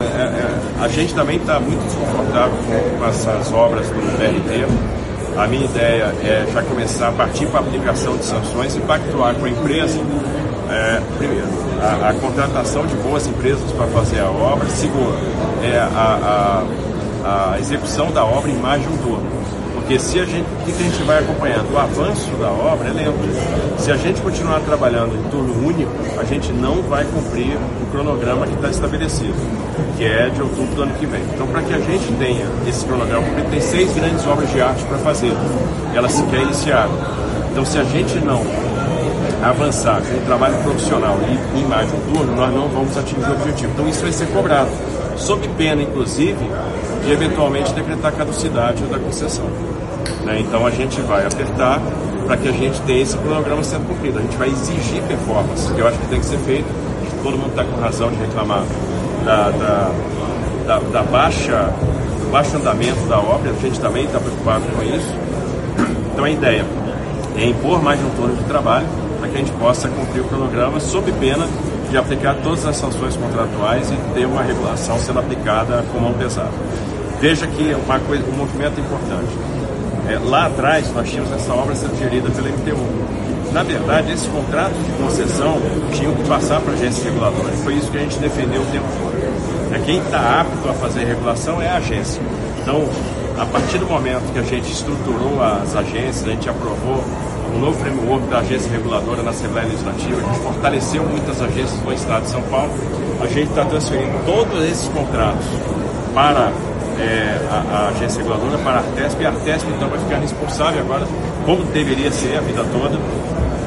A informação é do próprio governador Tarcísio de Freitas nesta sexta-feira, 29 de agosto de 2025, em entrevista coletiva durante entrega de novos equipamentos de saúde no Hospital Estadual Mário Covas, no bairro Paraíso, em Santo André, uma das regiões atendidas pelo Corredor ABD de ônibus e trólebus, que faz parte da concessão da NEXT Mobilidade no ABC, e funciona desde 1986.